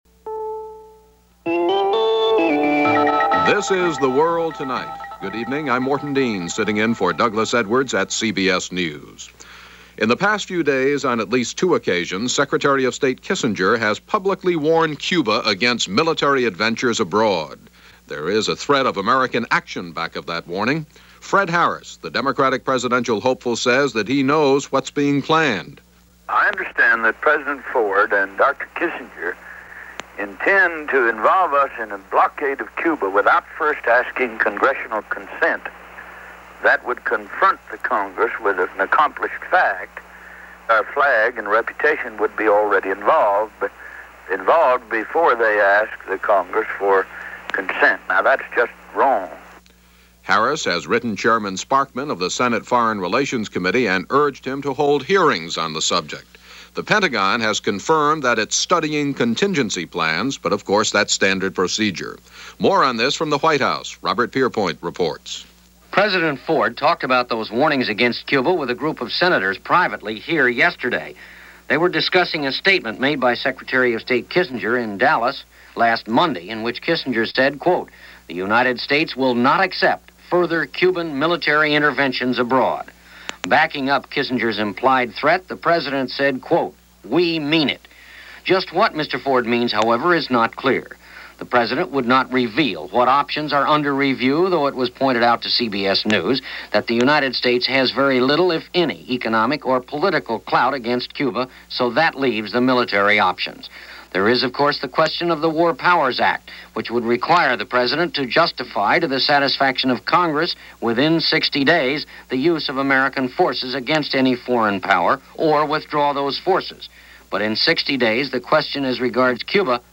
Cuba Showdown Redux - Swine Flu - Forever Beirut - March 25, 1976 - latest news from CBS The World Tonight